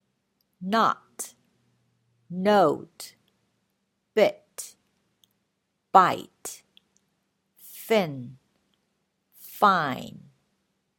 not” ＝「ダメ」 – “note” ＝「ノート」
bit” ＝「少し」 –  “bite” ＝「噛む」